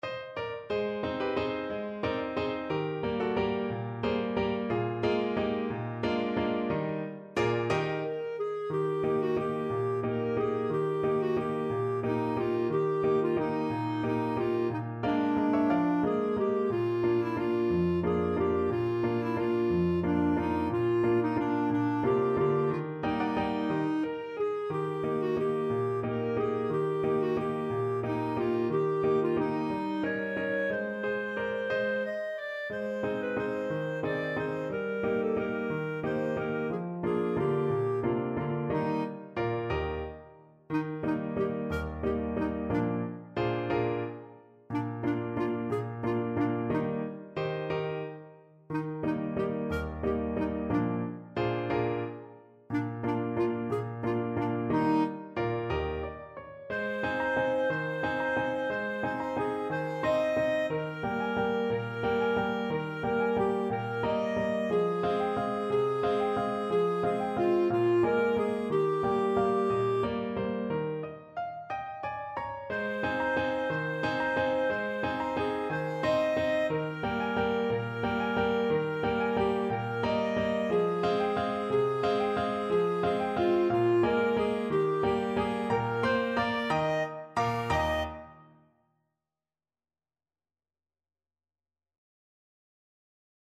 One in a bar .=c.60
3/4 (View more 3/4 Music)